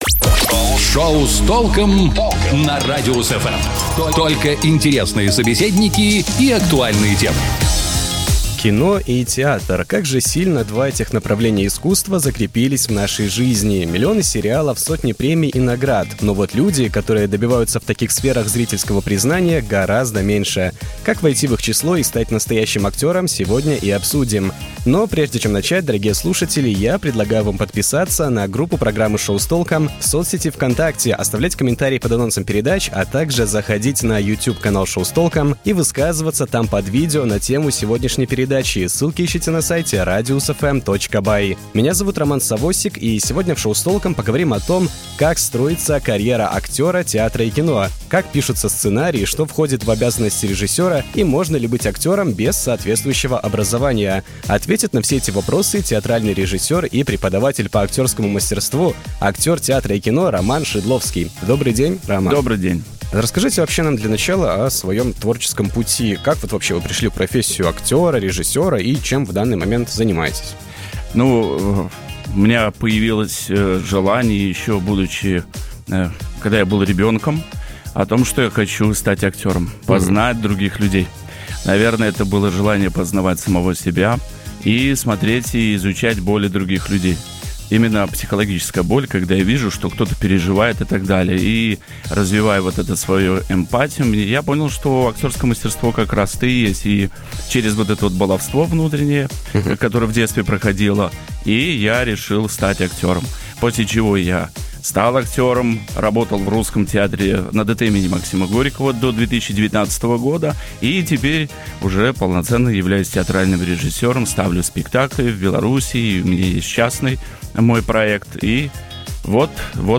театральный режиссер и преподаватель по актерскому мастерству, актер театра и кино